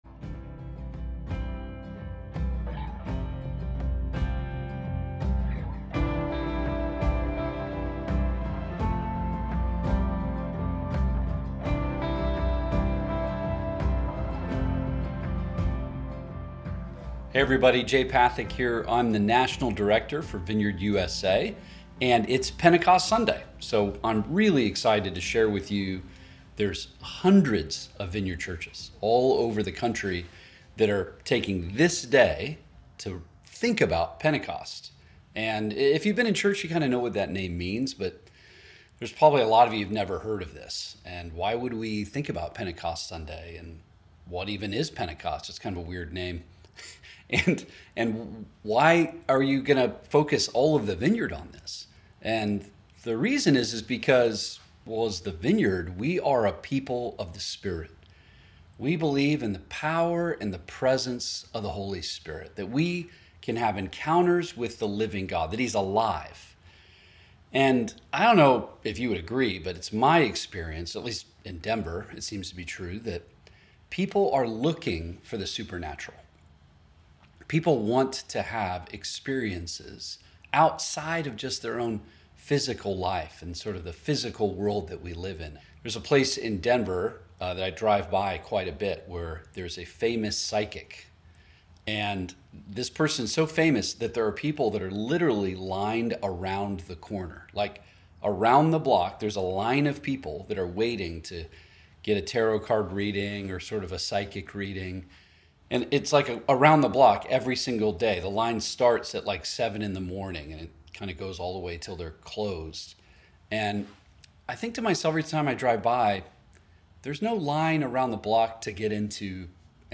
brings us today's message. Acts 1:8; Acts 2:1-13; Acts 2:17.